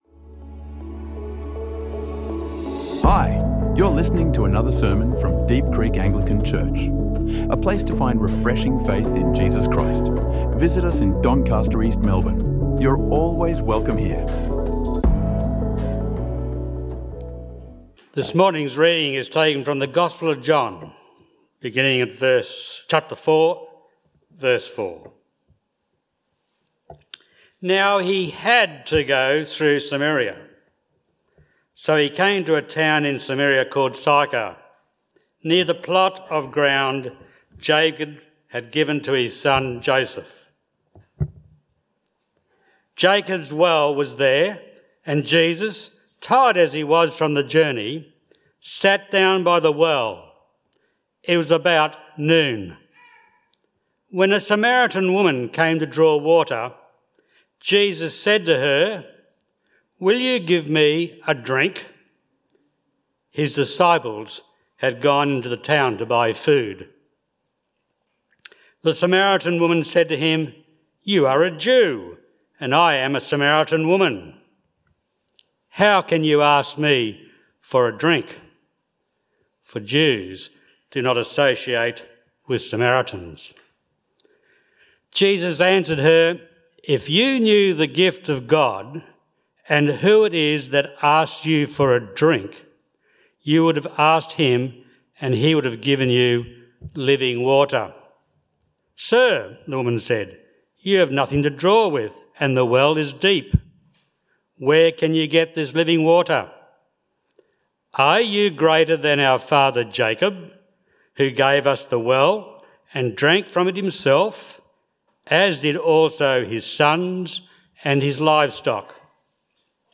| Sermons | Deep Creek Anglican Church